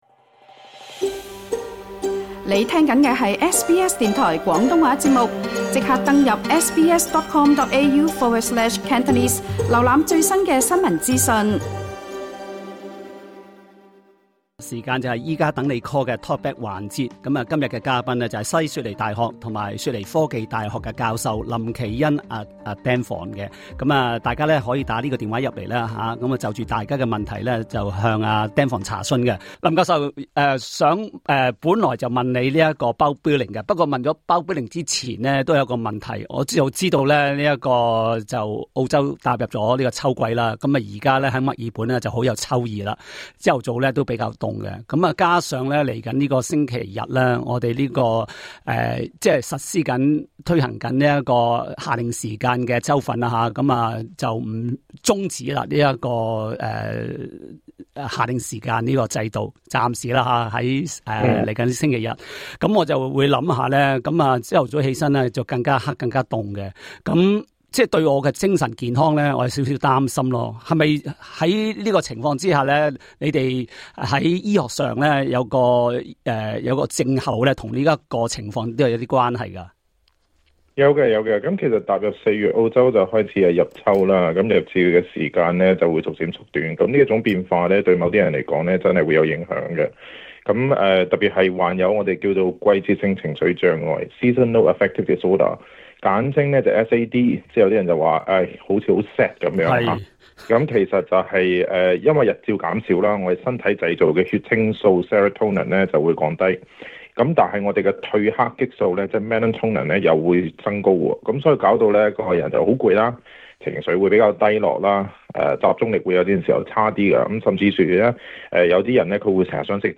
不少聽眾致電查詢與認知障礙症 (失智症) 的問題。 Credit: Pexels/Kindel Media 另外，有聽眾查詢身體出現蕁麻疹 (urticaria) 的原因及治療方法。